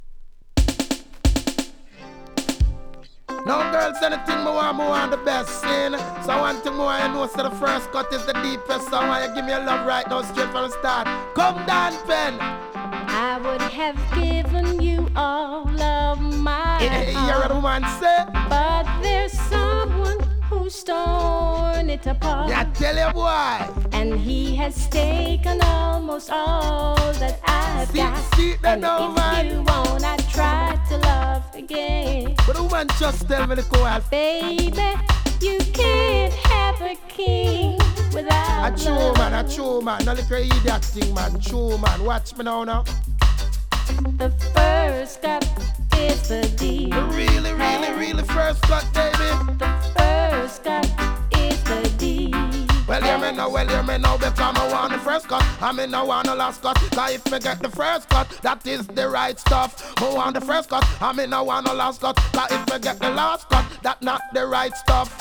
REGGAE 80'S
ソウル・カバーにDJが絡むお馴染みチューン♪